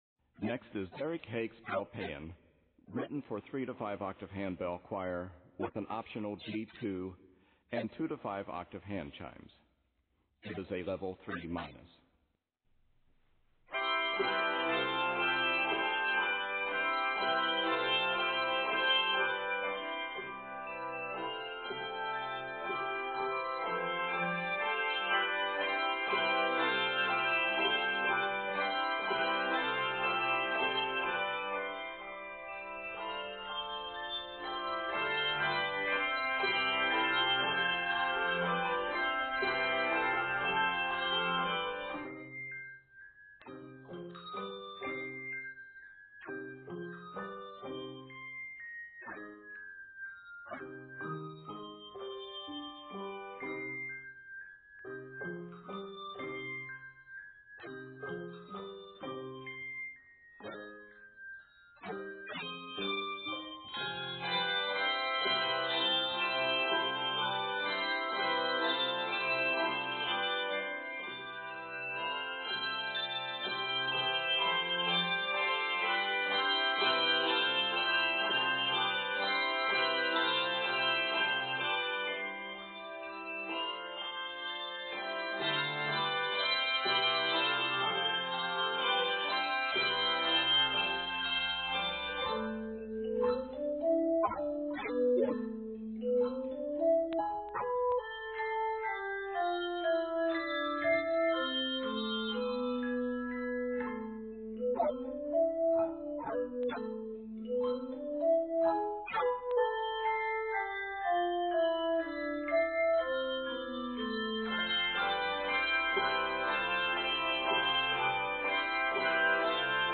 N/A Octaves: 3-5 Level